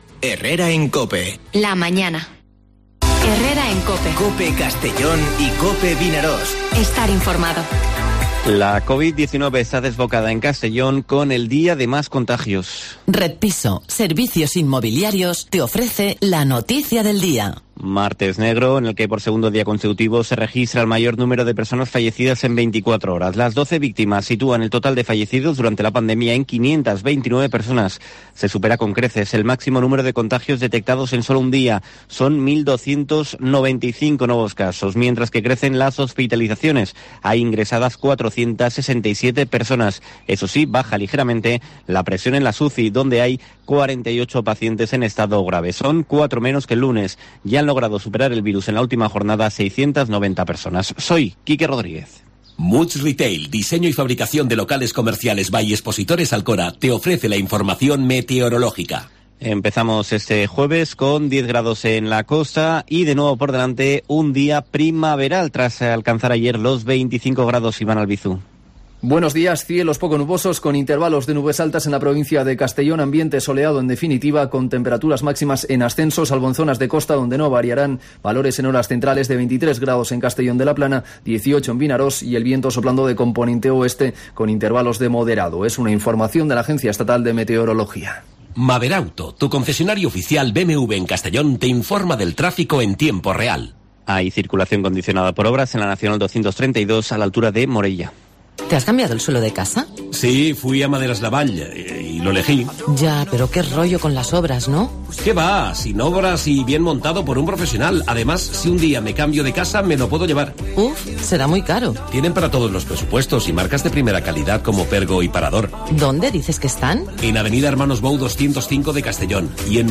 Informativo Herrera en COPE en la provincia de Castellón (28/01/2021)